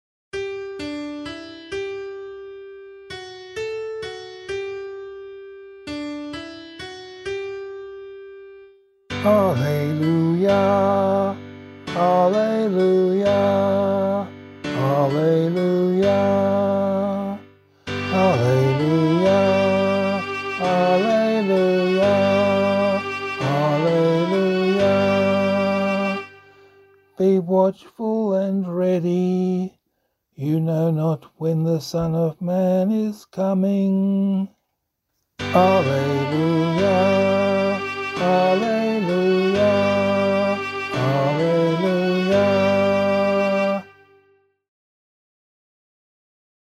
Gospel Acclamation for Australian Catholic liturgy.
066 Ordinary Time 32 Gospel A [LiturgyShare F - Oz] - vocal.mp3